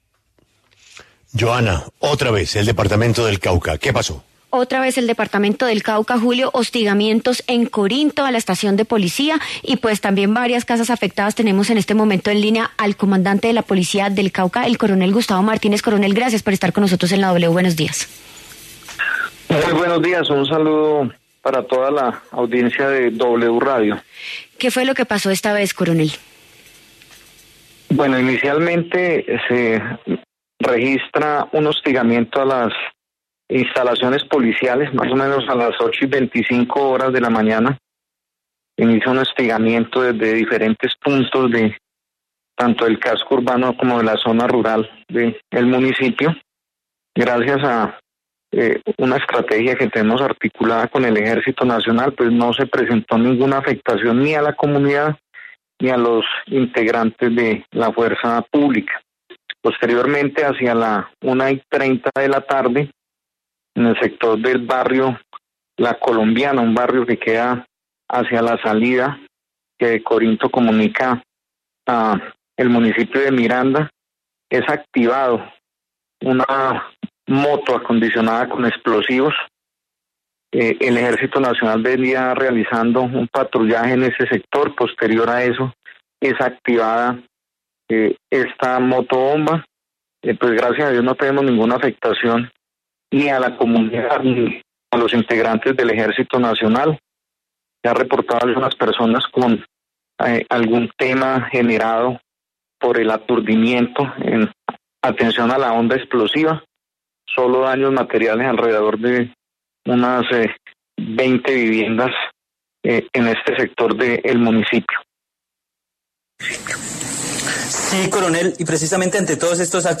El coronel Gustavo Martínez, comandante del departamento de Policía de Cauca, se pronunció en La W sobre los atentados directos contra la fuerza pública.